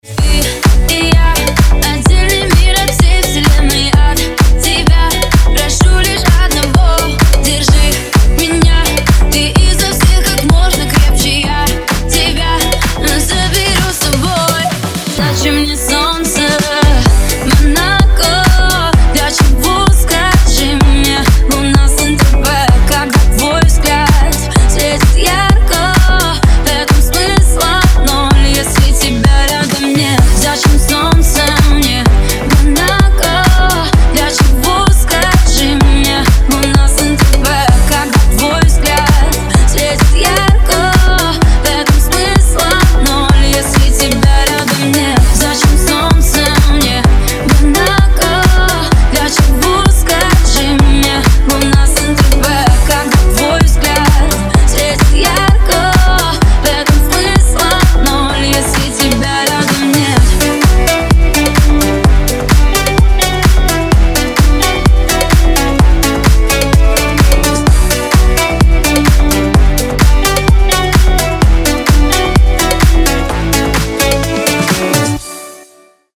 • Качество: 320, Stereo
Club House